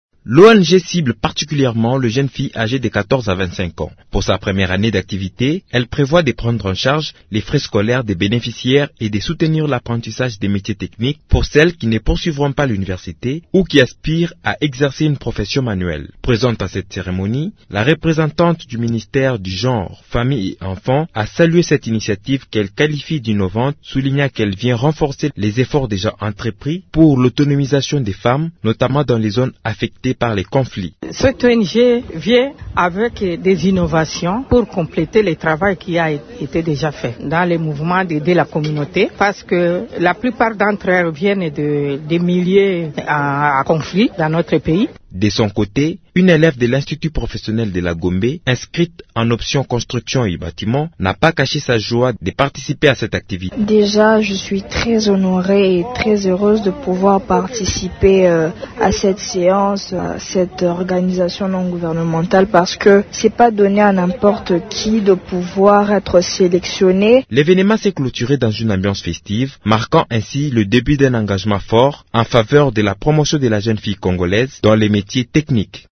Les détails dans ce reportage